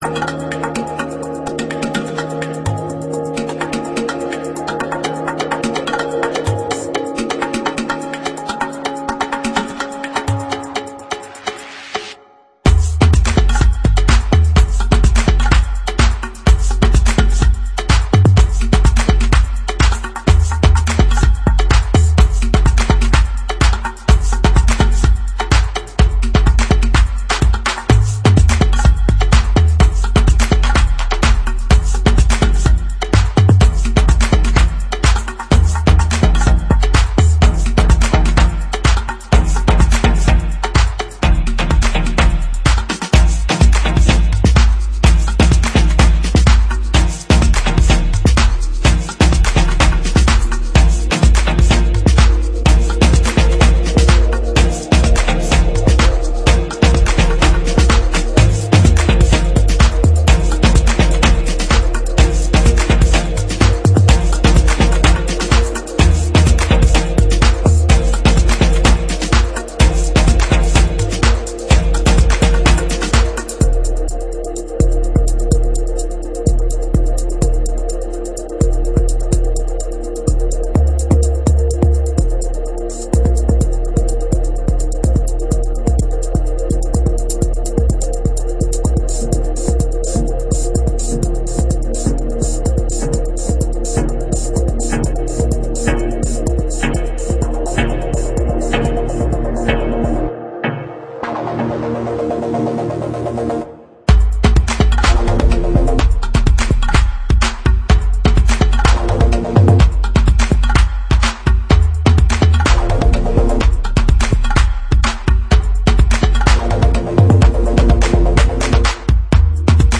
supplier of essential dance music
House